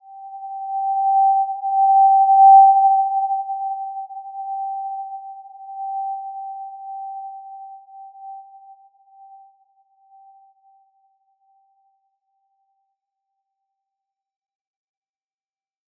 Simple-Glow-G5-p.wav